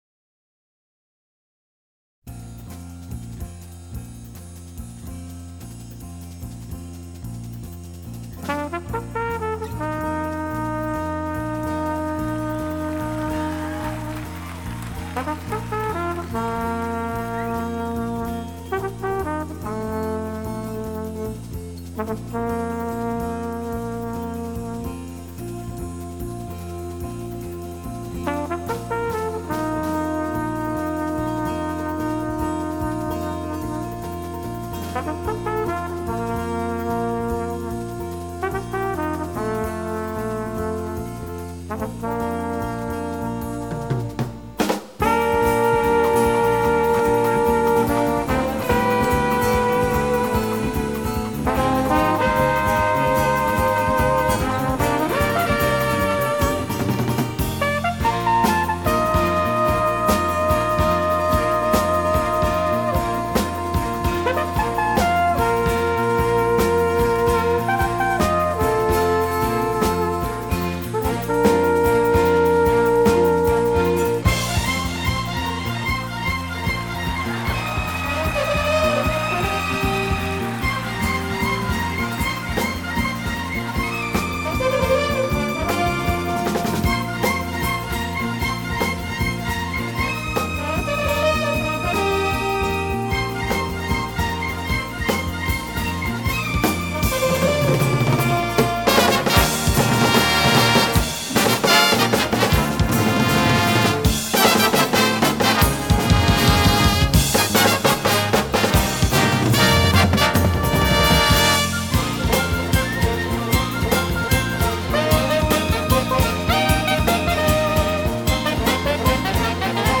jazz en directo
Era un concierto en el Hollywood Bowl de Los Ángeles
La indiscutible elegancia del fiscornio
Live At The Hollywood Bowl